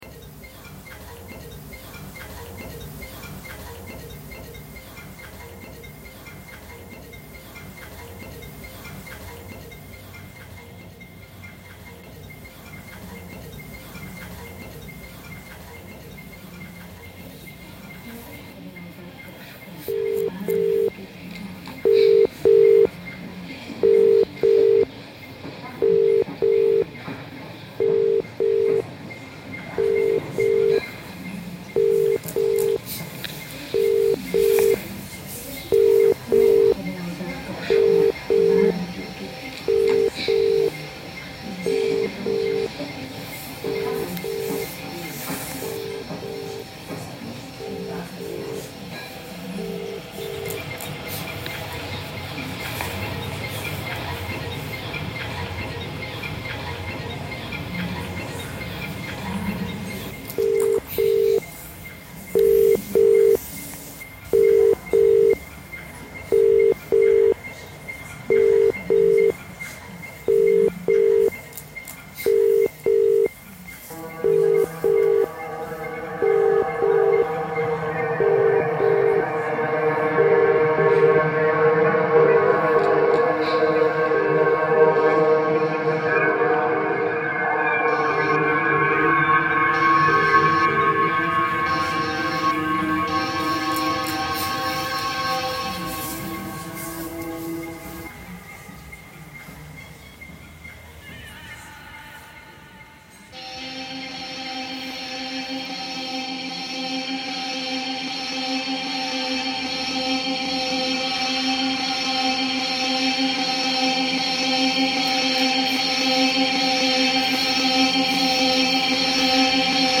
Mourning song in Dushanbe reimagined